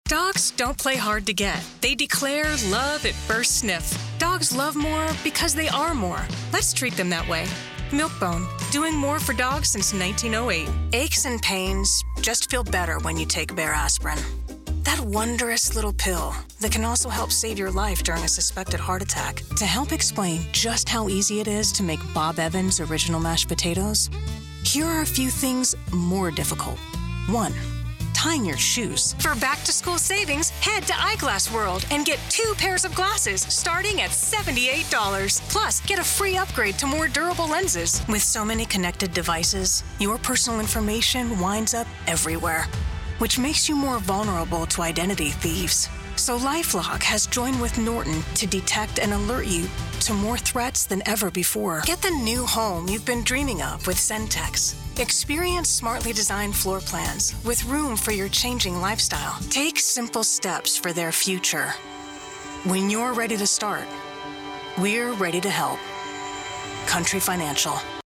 Female Voiceover
Commercial
Infuse your commercial scripts with a conversational tone that effortlessly resonates with listeners across TV ads, radio spots, web promos, or PSAs.